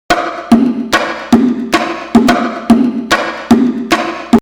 Turning it down to a more usable level we get this:
bongoafter.mp3